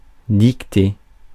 Ääntäminen
Ääntäminen France: IPA: [dik.te] Haettu sana löytyi näillä lähdekielillä: ranska Käännös Substantiivit 1. dictation 2. dictate 3.